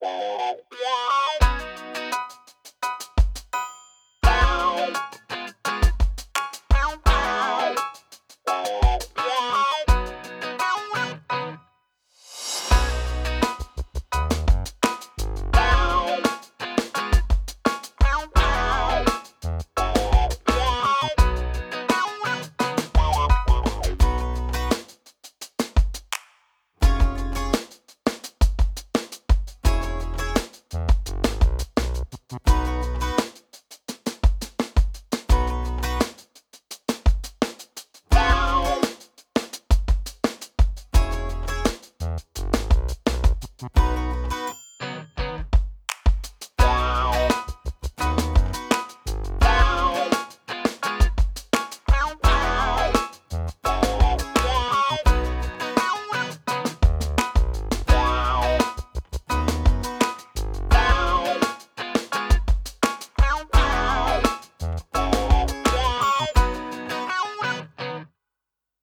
Production Music Examples
Funky Pop (Ad, Reality)